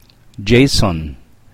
Výslovnost filipínských jmen
Naši kolegové z Filipín nám nahráli správnou výslovnost vybraných jmen.